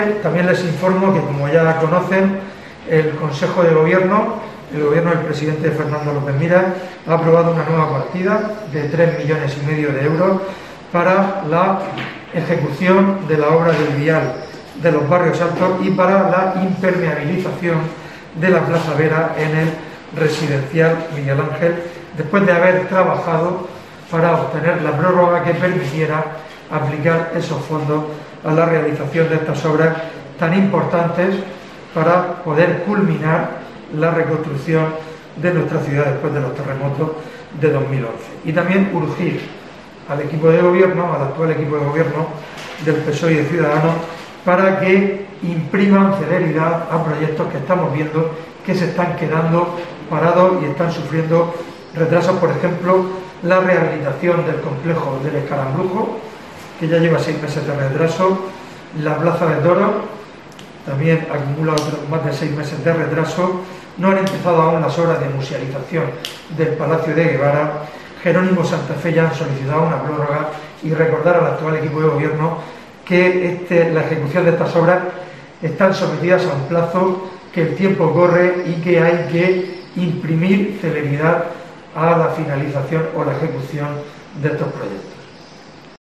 Fulgencio Gil, portavoz del PP sobre inversiones